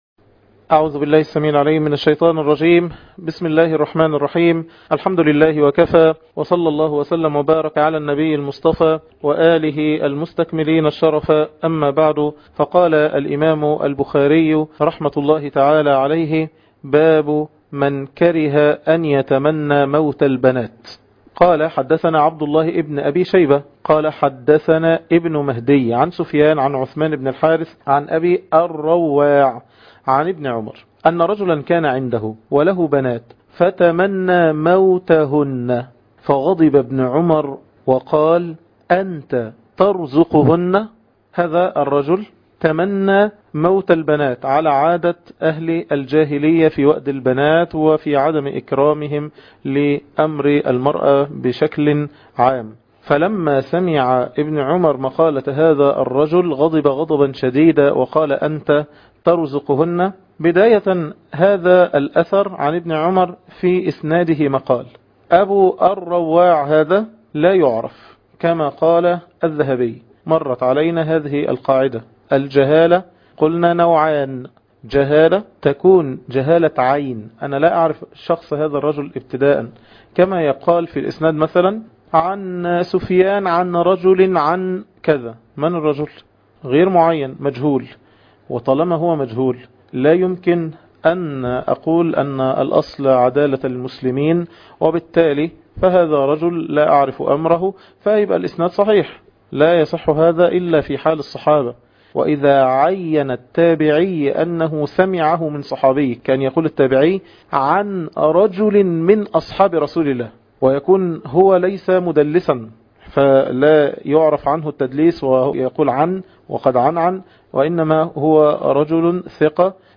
٩- فضل الرزق بالبنات - شرح كتاب الأدب المفرد للإمام البخاري